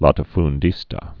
(lätə-fn-dēstə)